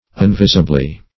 unvisibly - definition of unvisibly - synonyms, pronunciation, spelling from Free Dictionary Search Result for " unvisibly" : The Collaborative International Dictionary of English v.0.48: Unvisibly \Un*vis"i*bly\, adv.